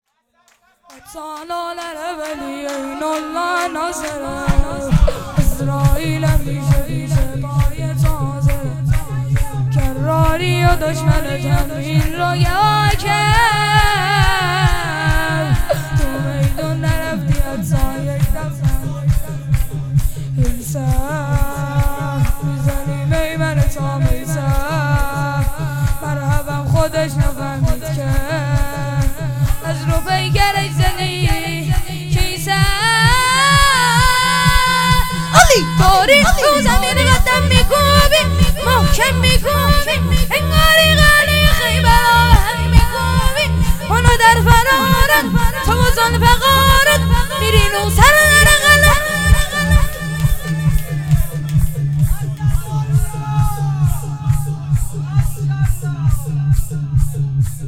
شور | قاتل العرب علی